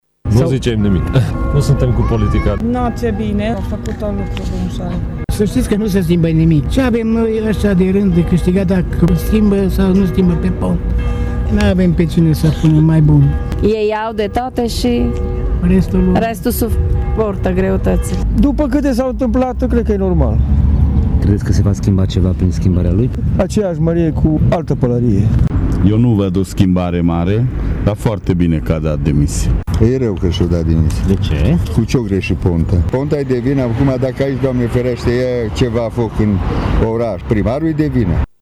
Mureşenii au opinii împărţite faţă de demisia premierului Victor Ponta.